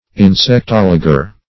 Insectologer \In`sec*tol"o*ger\, n. An entomologist.